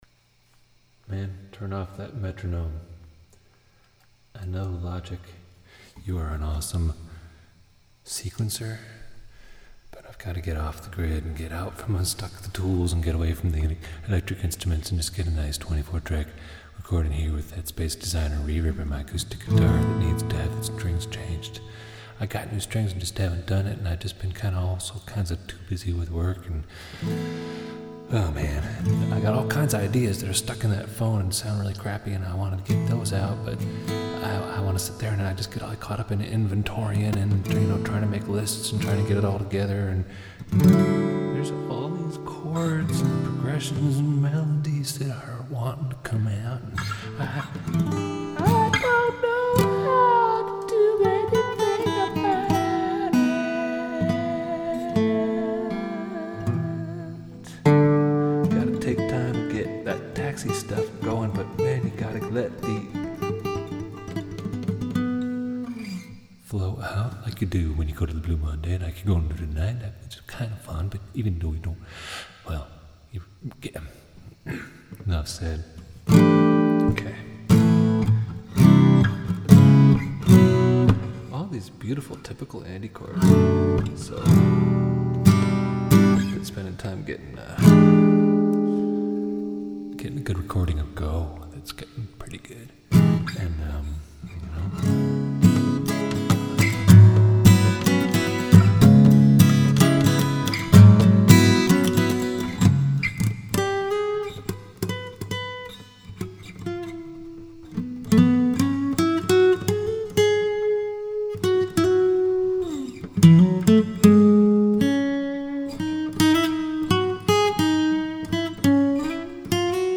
acoustic guitar and vocal captured from a cassette tape improvisation Recorded in 2005 in mangobananas studio, Ithaca, NY.